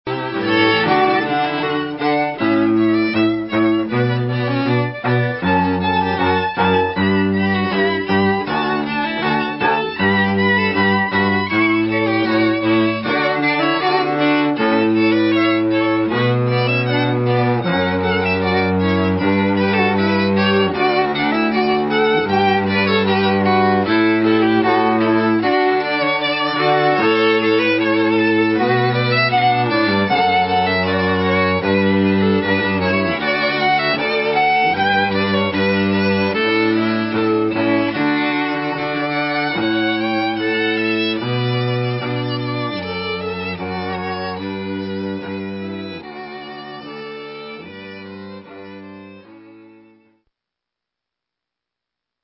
Violinist
Wedding Ceremony and Prelude